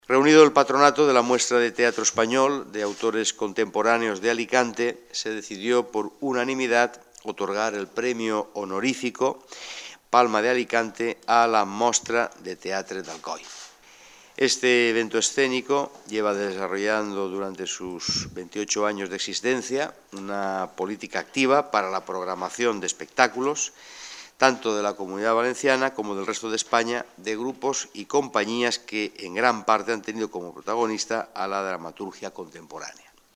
En este sentido, el diputado, encargado de leer el Acta del Jurado, ha destacado “la solvencia y calidad” de las obras que este año componen la programación de un evento “que cada vez reúne a un mayor número de público”.